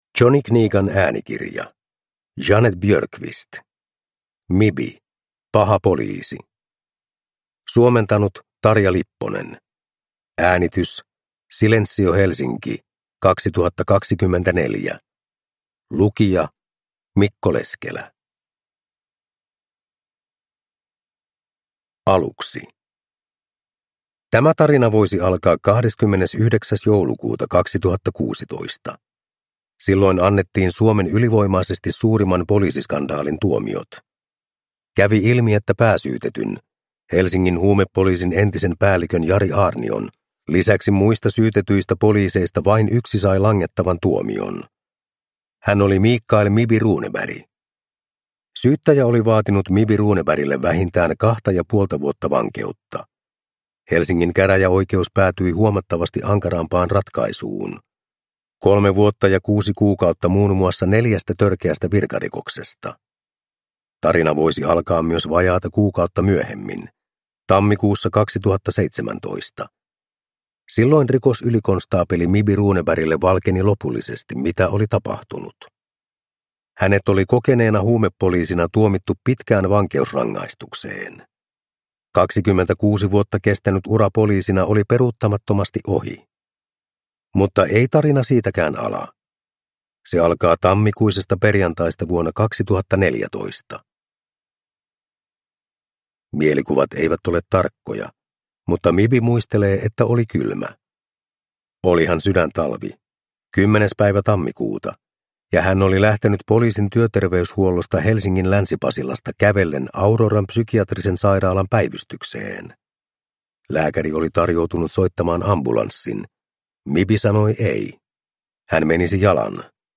Mibi – Paha poliisi (ljudbok) av Jeanette Björkqvist